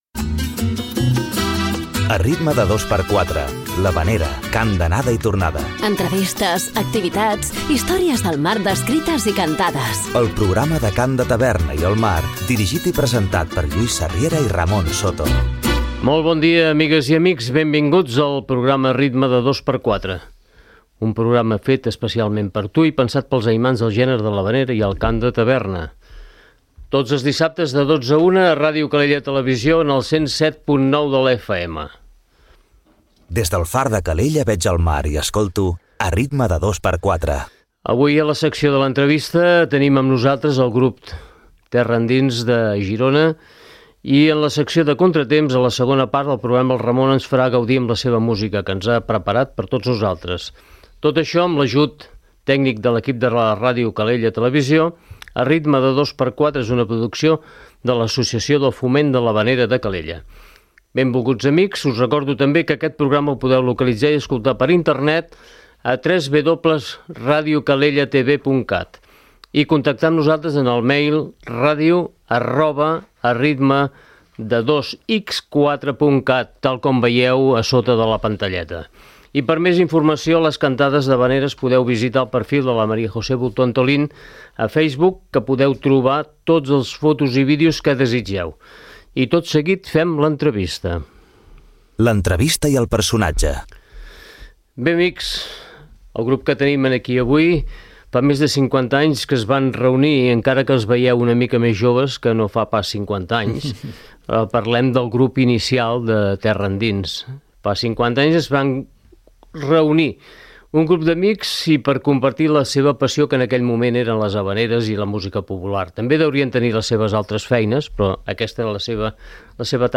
Avui a la secció de l'entrevista tenim amb nosaltres 3 dels components actuals del grup Terra Endins.